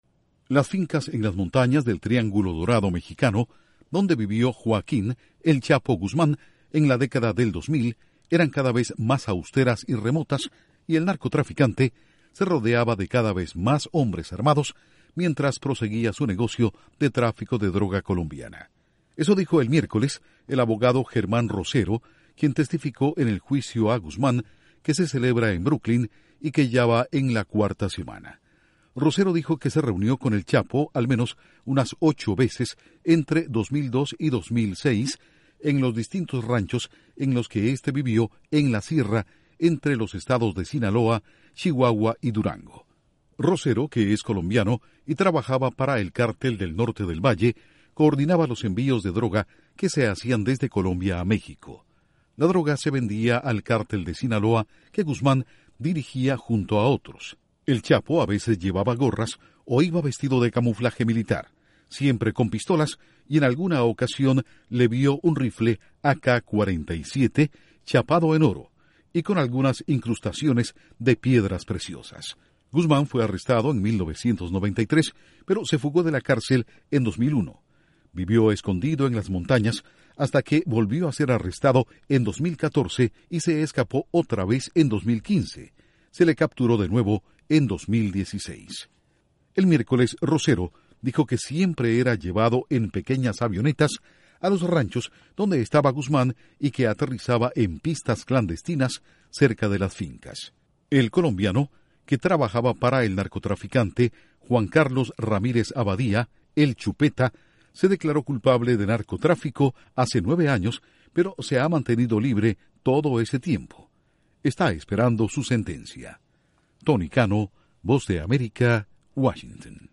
Testigo habla de reuniones con “El Chapo” en las montañas de México. Informa desde la Voz de América en Washington